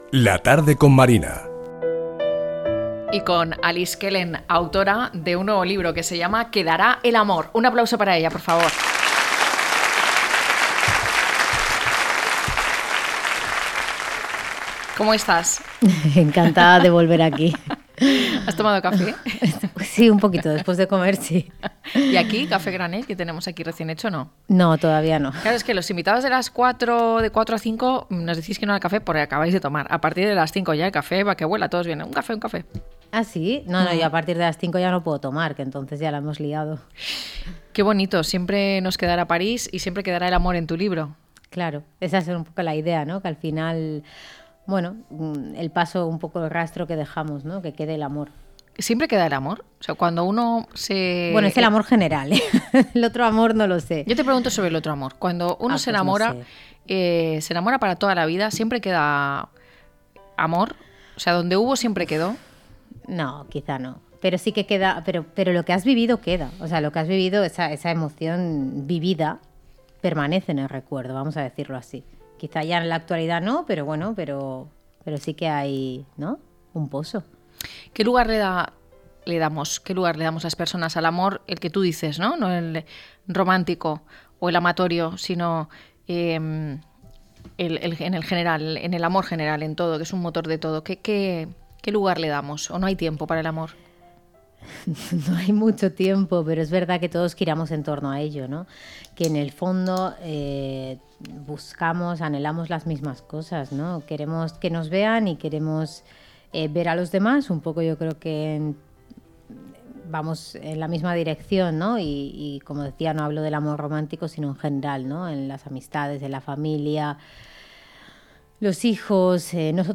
ENTREVISTA CON ALICE KELLEN “La belleza de lo triste”, así describen los lectores de forma luminosa la nueva novela, “Quedará el amor”, de la escritora valenciana Alice Kellen. A través de unos personajes inolvidables y una historia mágica, la novelista se dirige a un público adulto con un homenaje al sentimiento universal reflejado en su título y al valor de la amistad y la esperanza.